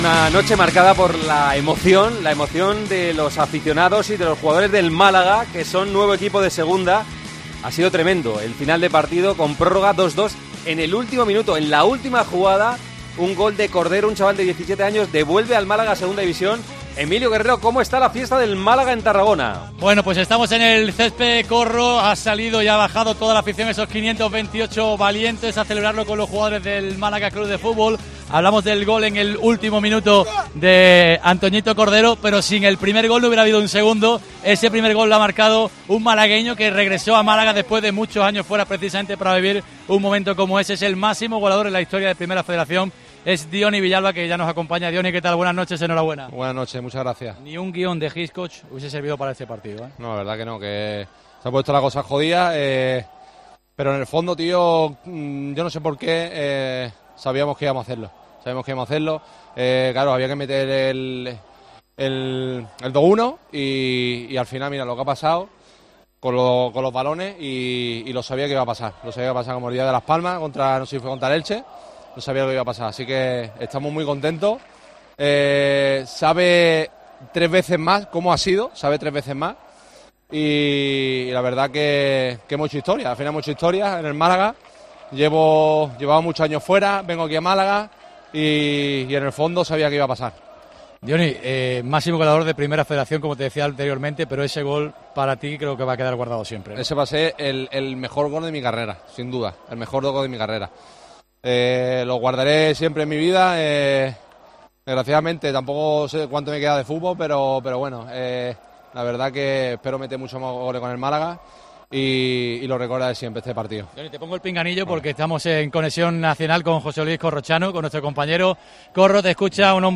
Con Paco González, Manolo Lama y Juanma Castaño